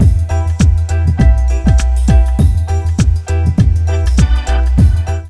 RAGGALOOP1-L.wav